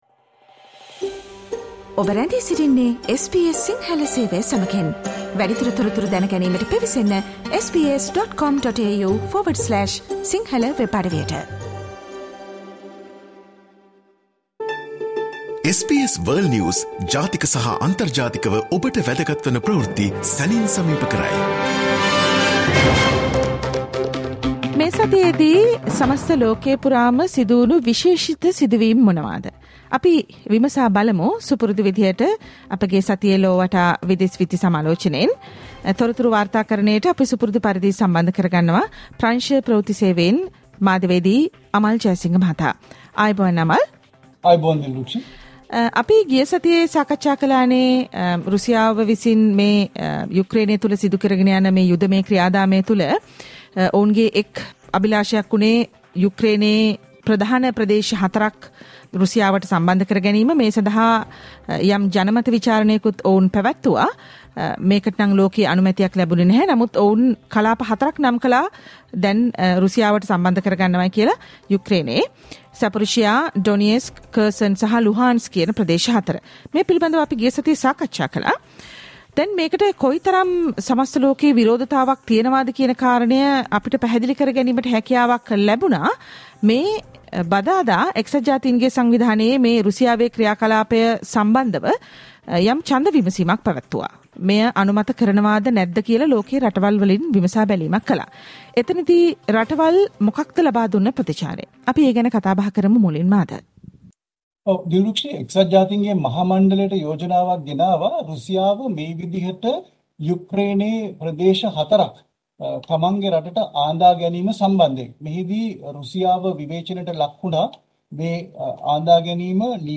World's prominent news highlights in a few minutes - listen to the SBS Sinhala Radio weekly world News wrap every Friday